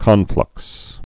(kŏnflŭks)